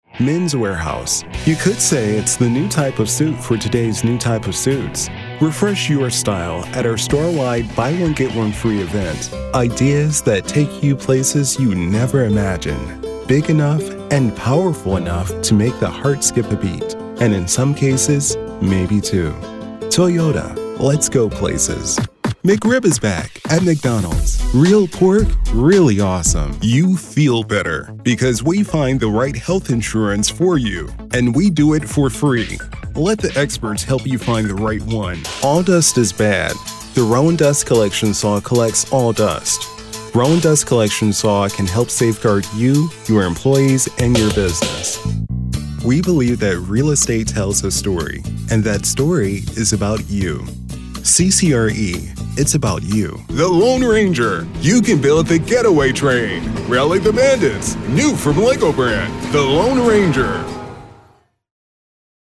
Calming, Bass, Friendly